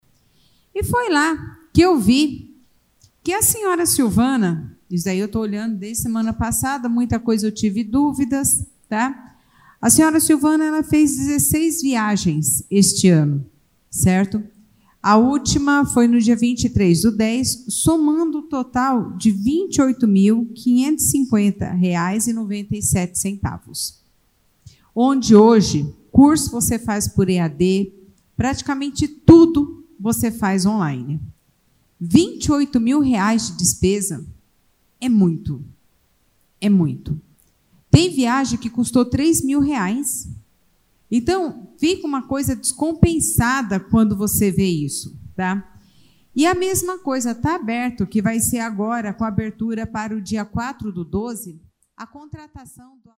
Ouça parte do discurso de Alessandra tocando neste assunto: